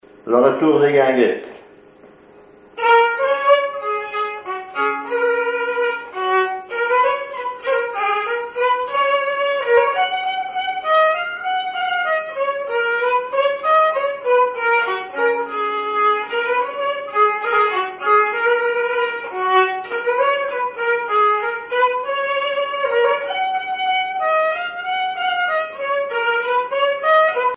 violon
valse musette
Répertoire au violon
Pièce musicale inédite